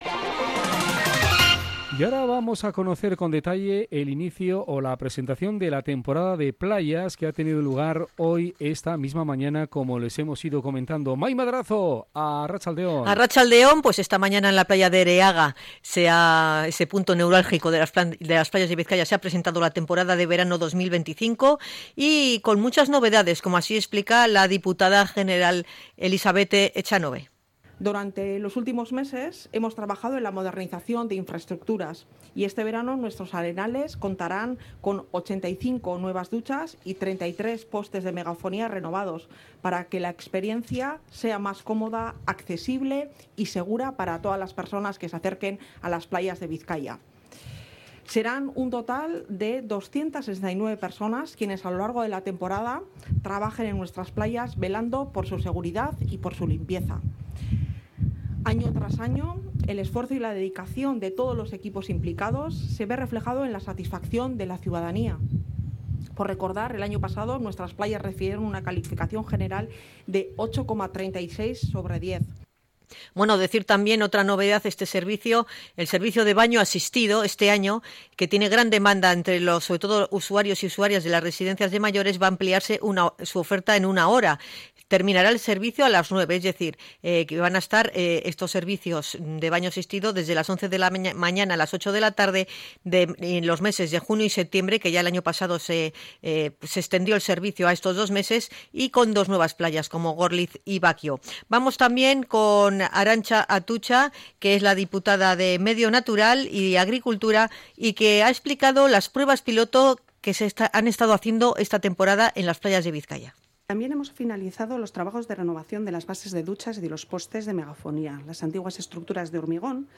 Las responsables de la Diputación han presentado las novedades en la playa de Ereaga
La presentación de la temporada de playas ha tenido lugar hoy en Ereaga y a ella han acudido la Diputada General, Elixabete Etxanobe, la diputada de Medio Natural y Agricultura, Arantza Atutxa y la alcaldesa de Getxo Amaia Agirre.